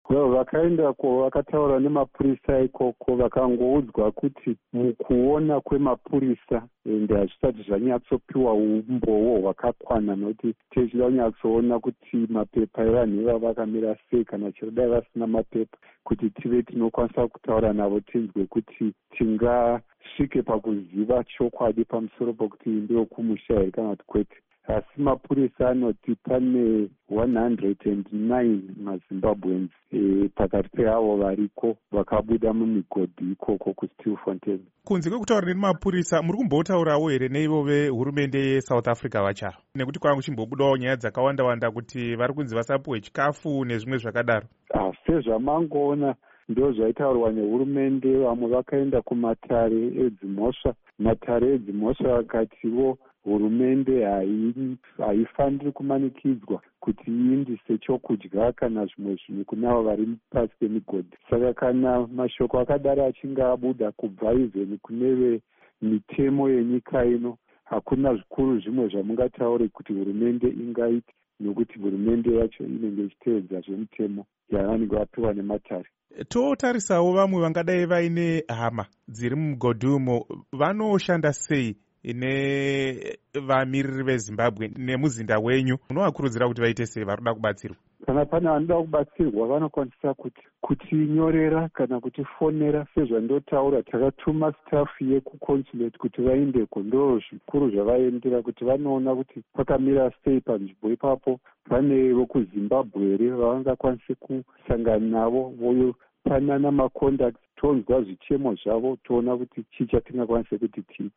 Hurukuro naVaDavid Hamadziripe